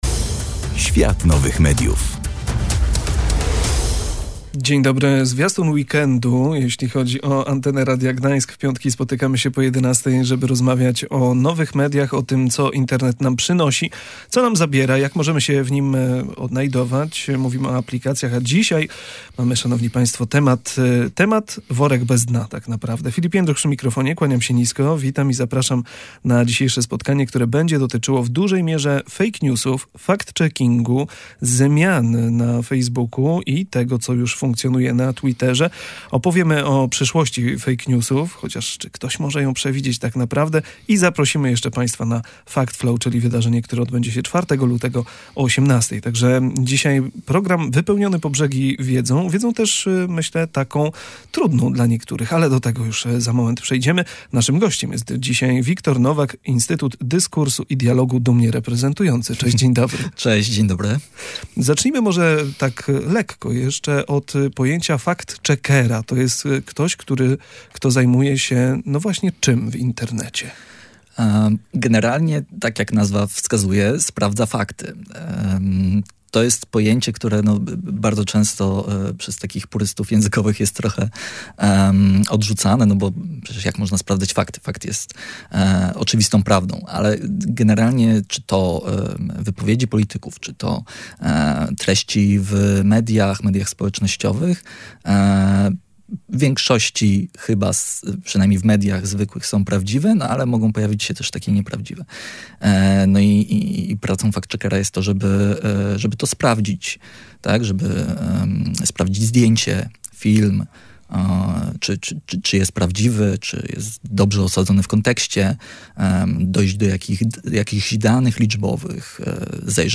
Fact-checking to proces weryfikacji informacji mający na celu rozpoznanie i „rozbrajanie” fake newsów. Nasz gość zwrócił uwagę na rolę krytycznego myślenia, które powinno towarzyszyć nam w trakcie przyswajania treści internetowych.